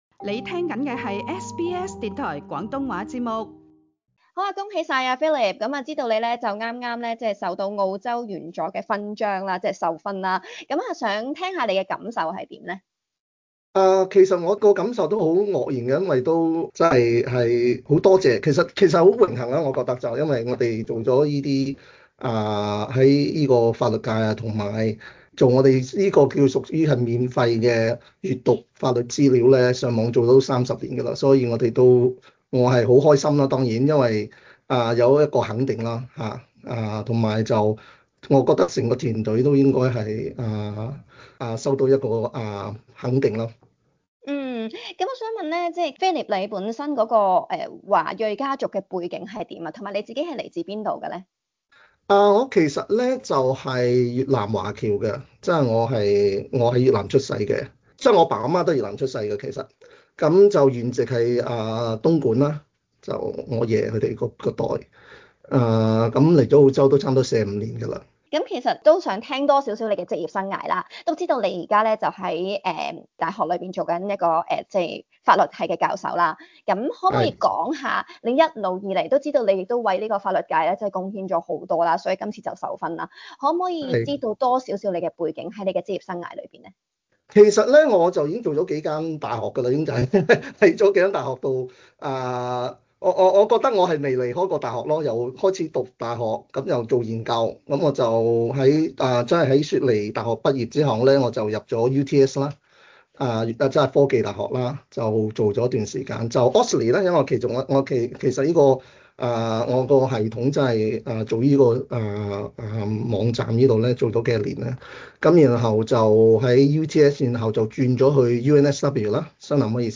他接受SBS廣東話 訪問 時表示，過去30年，雖然工作的地方一直在變，但最重要的方針仍然是服務社會。